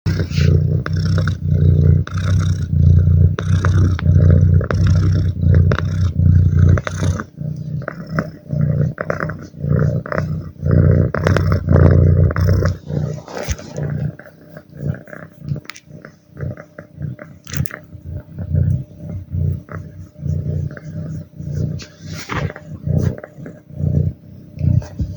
Gentle Lion Purr Botón de Sonido
Play and download the Gentle Lion Purr sound effect buttons instantly!